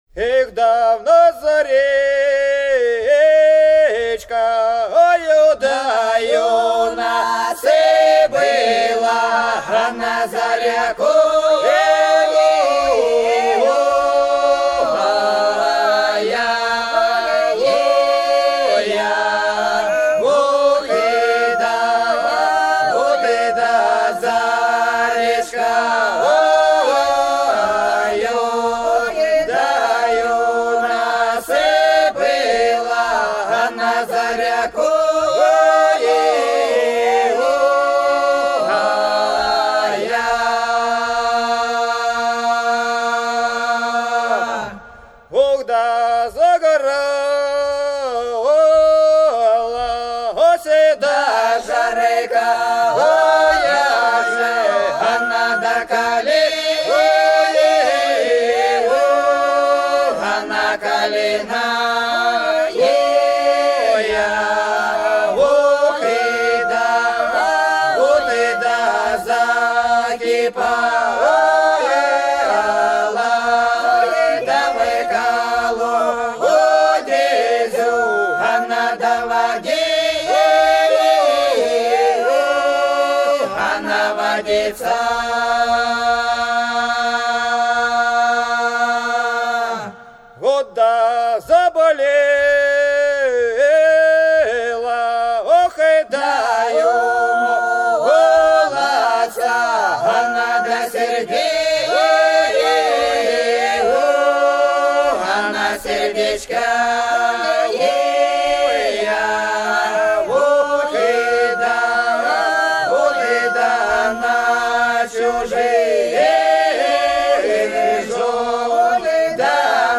Долина была широкая (Поют народные исполнители села Нижняя Покровка Белгородской области) В нас за речкою - протяжная